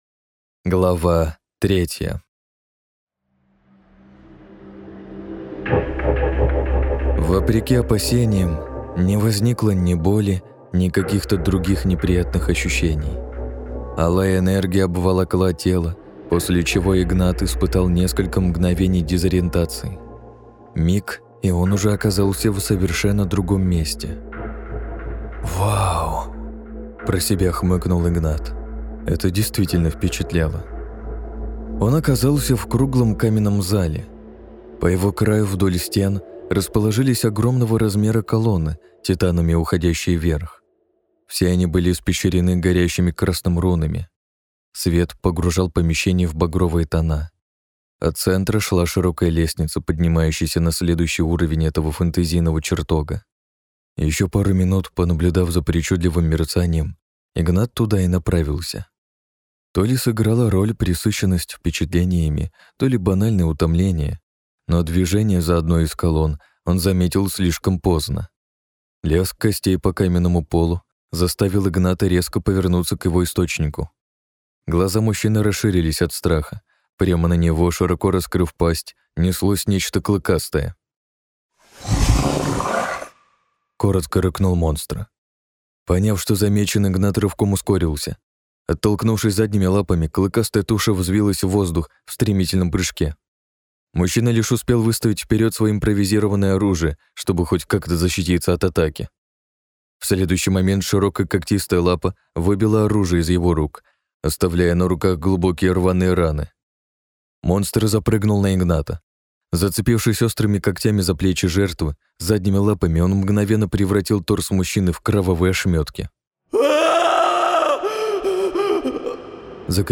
Проводник Хаоса. Книга 1 - Евгений Понарошку. Аудиокнига - слушать онлайн